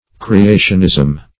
creationism - definition of creationism - synonyms, pronunciation, spelling from Free Dictionary
Creationism \Cre*a"tion*ism\ (-?z'm), n.